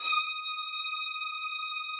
strings_075.wav